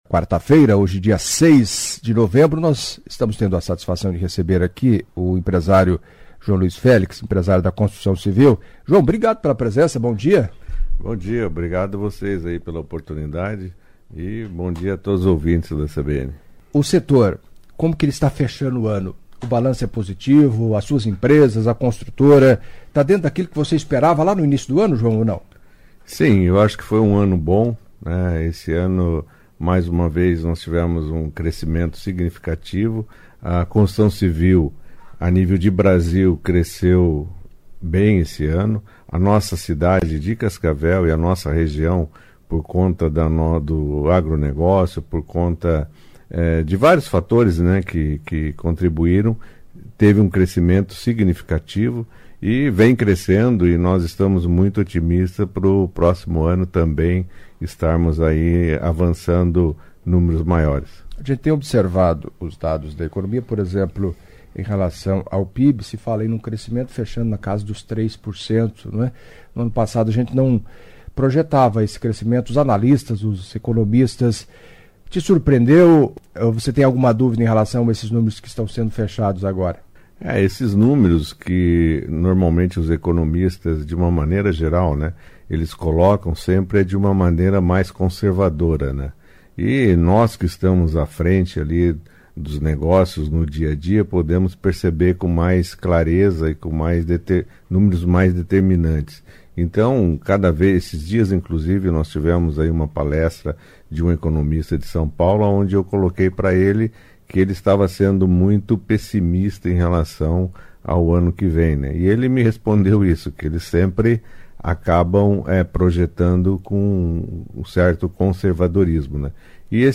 Em entrevista à CBN Cascavel nesta quarta-feira (06)